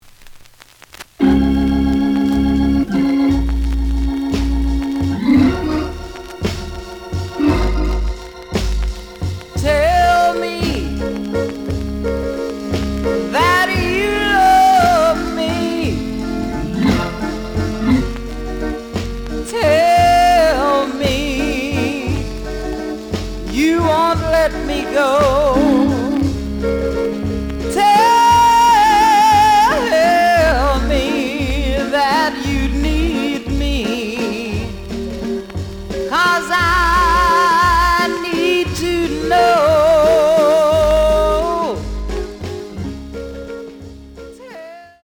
試聴は実際のレコードから録音しています。
●Format: 7 inch
●Genre: Soul, 60's Soul